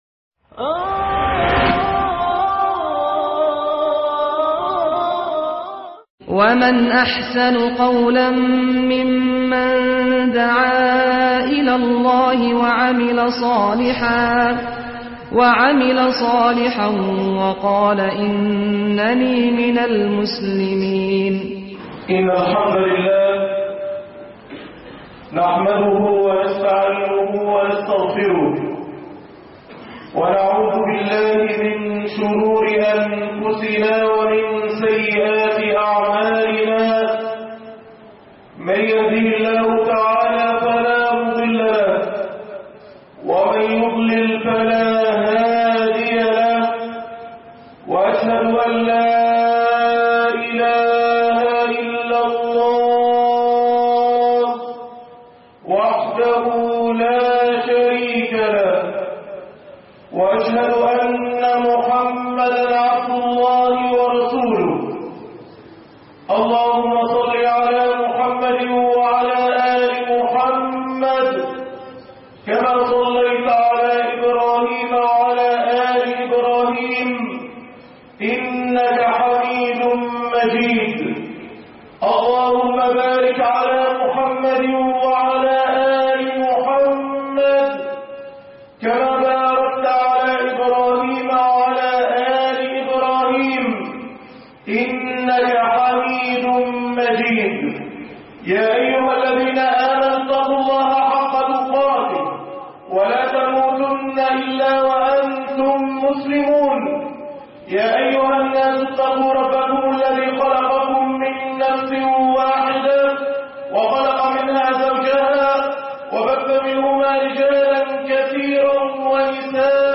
ذَلِكَ لِمَنْ خَافَ مَقَامِي وَخَافَ وَعِيدِ"-خطب الجمعة